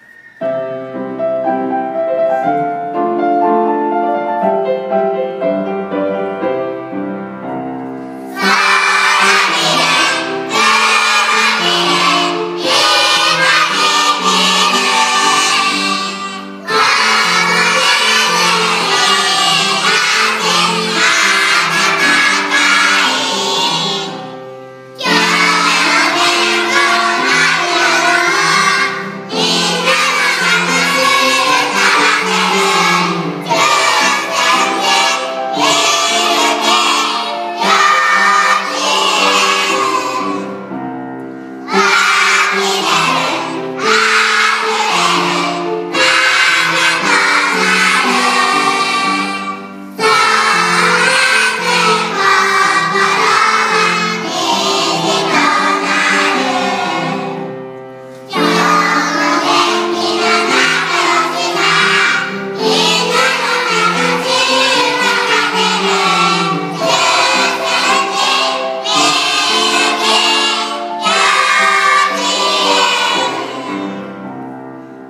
子どもたちの歌声が聞こえます！！
作詩　西村　重和
作曲　田辺　秀治